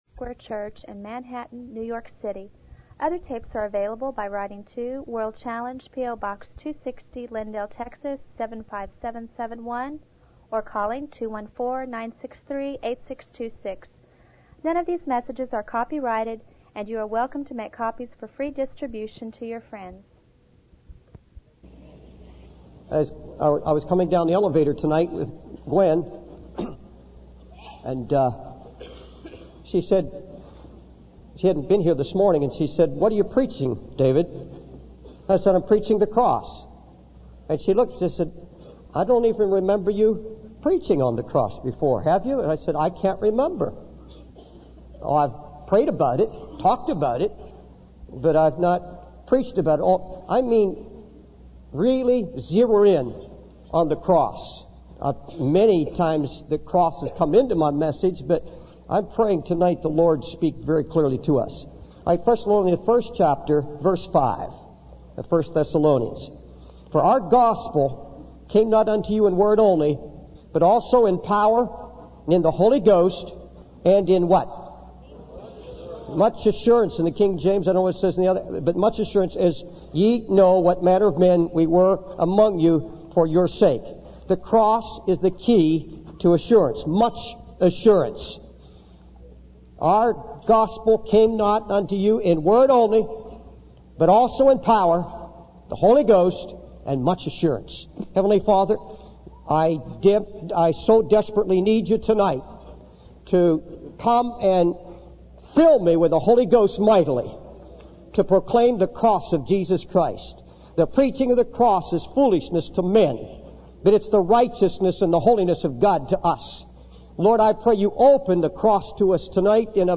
The preacher then shifts to the story of the Israelites crossing the Red Sea in Exodus 14, highlighting the victory of the cross in this chapter. He invites the congregation to experience freedom from the hold of the devil by trusting in Jesus' power to overcome the enemy.